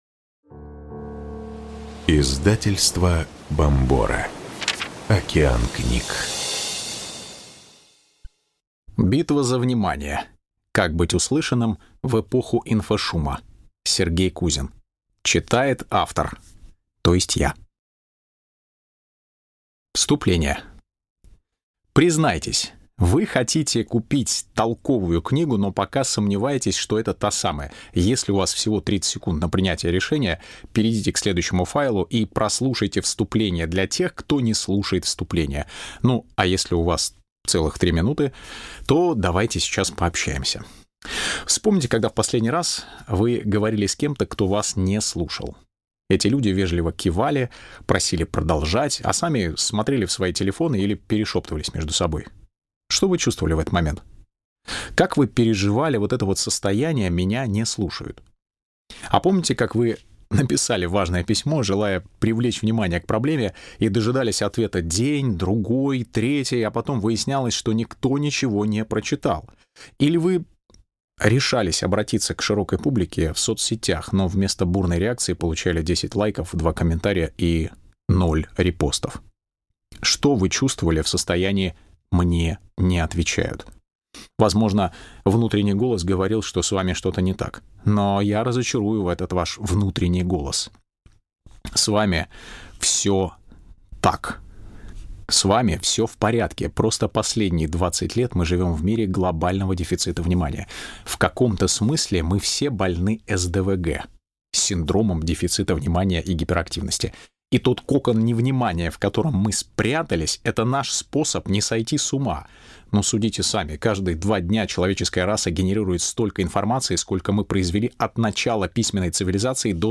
Аудиокнига Битва за внимание. Как быть услышанным в эпоху инфошума | Библиотека аудиокниг